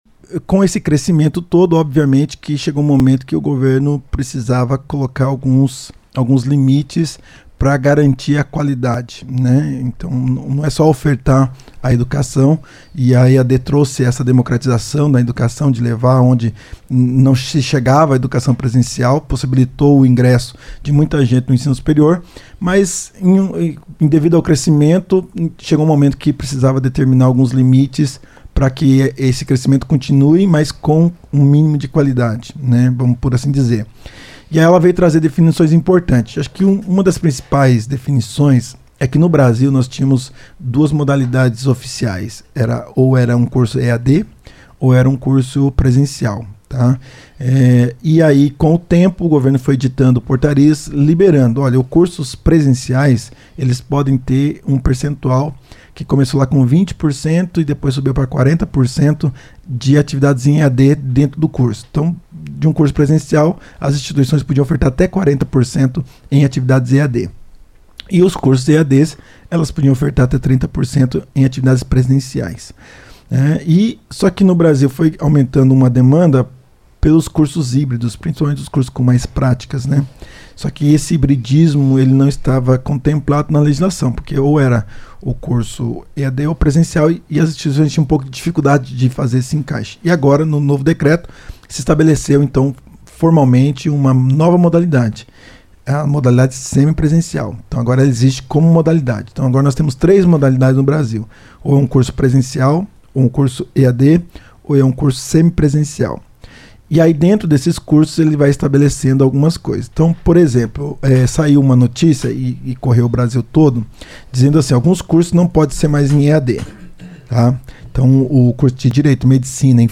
esteve na CBN e deu orientações sobre as alterações.